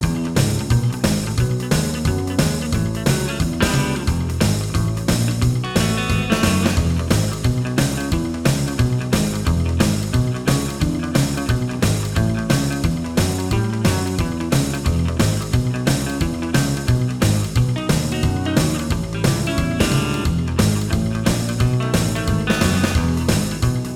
Minus Guitars Rock 'n' Roll 3:30 Buy £1.50